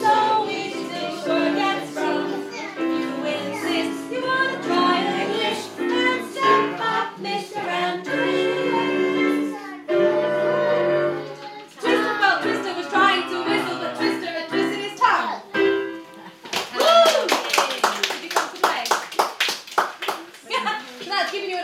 Toungue Twisting at Besson Street Gardens.